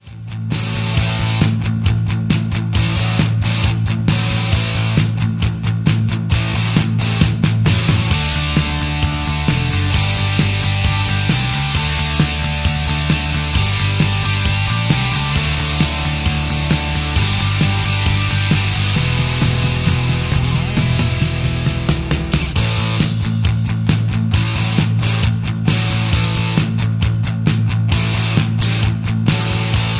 Backing track files: All (9793)
Buy Without Backing Vocals